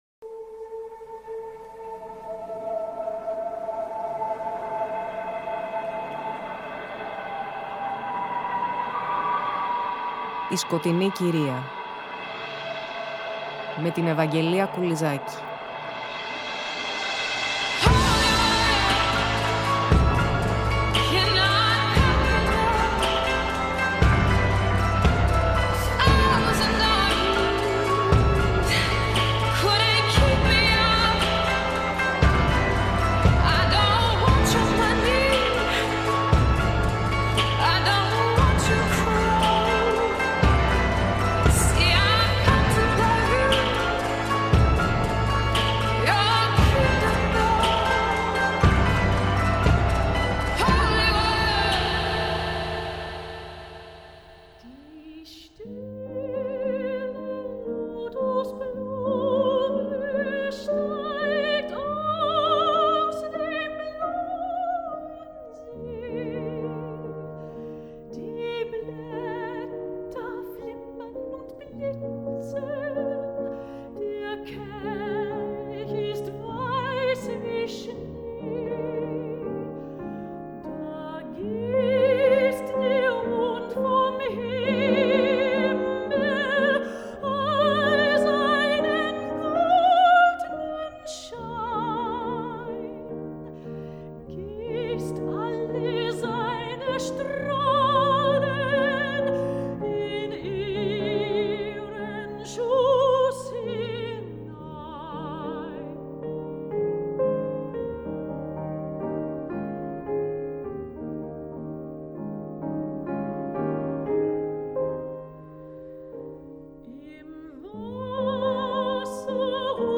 Διανθίζουμε την αφήγηση με αποσπάσματα από την αλληλογραφία τους και πλαισιώνουμε μουσικά με τραγούδια και συνθέσεις του ζεύγους, όπως και στις προηγούμενες εκπομπές του αφιερώματος.